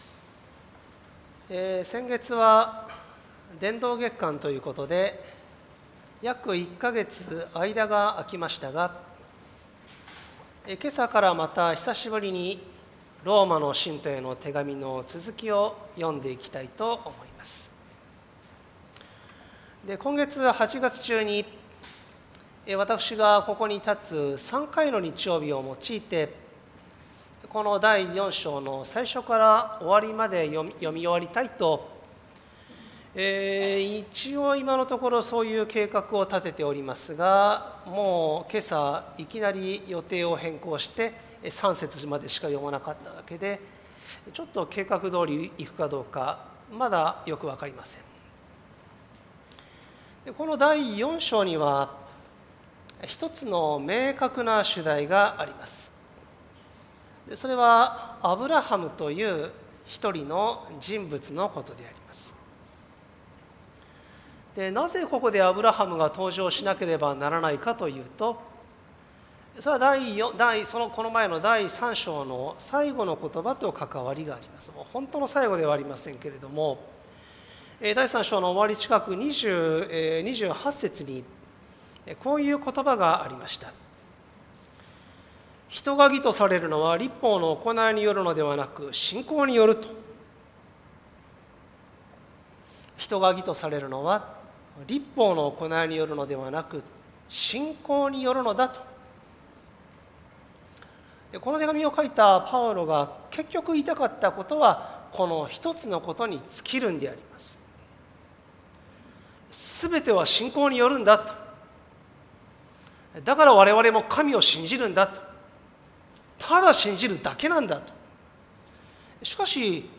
主日礼拝